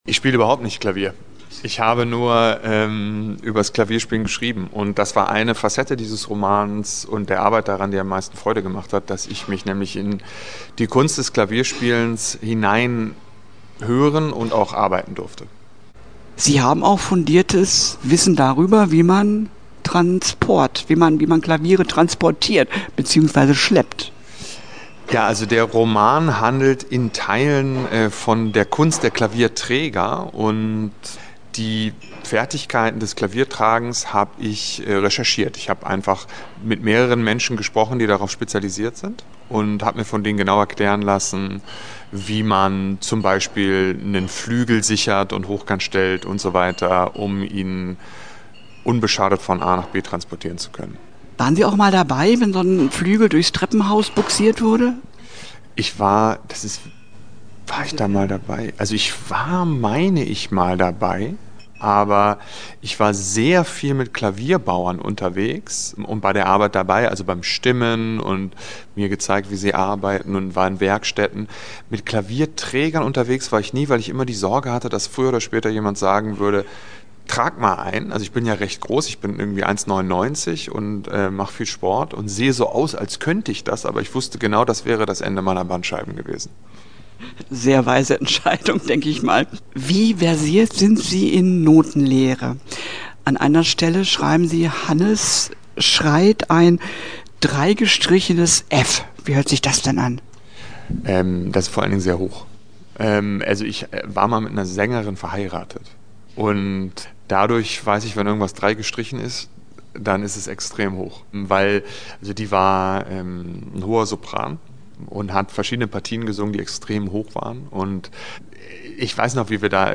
Interview-Takis-Wuerger.mp3